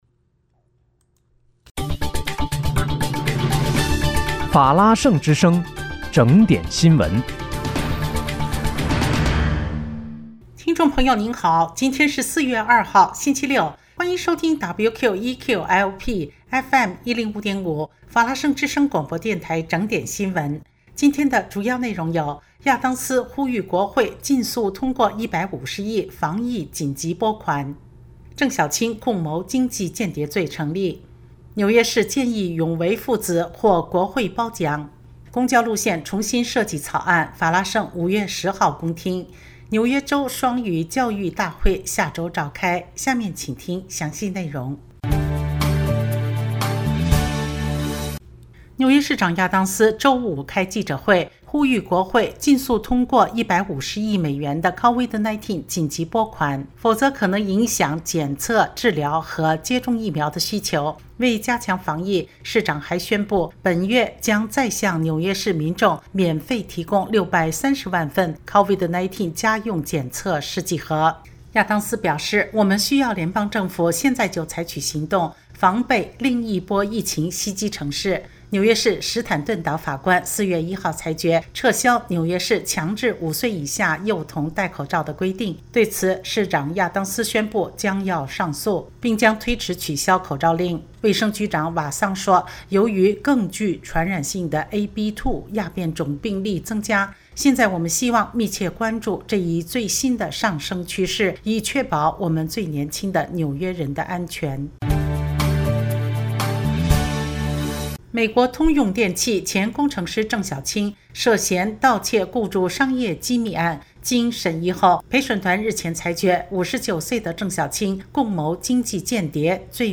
听众朋友您好！今天是4月2号，星期六，欢迎收听WQEQ-LP FM105.5法拉盛之声广播电台整点新闻。